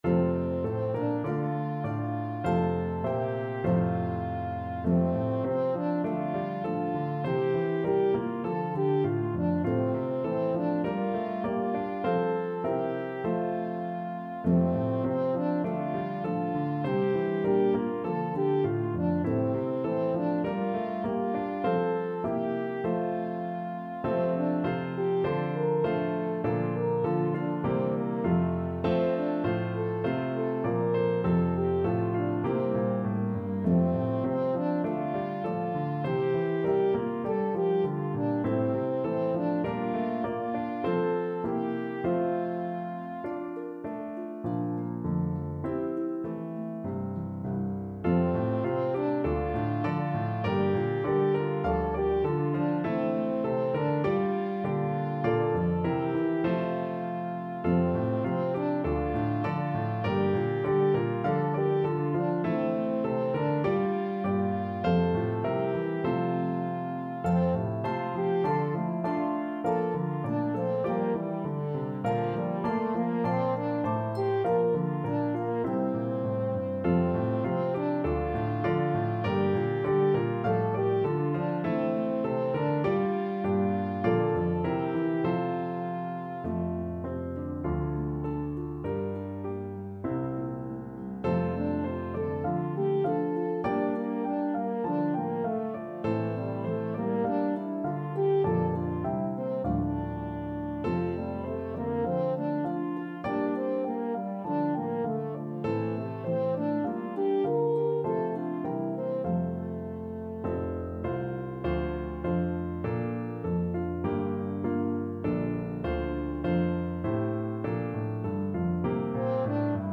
a pentatonic melody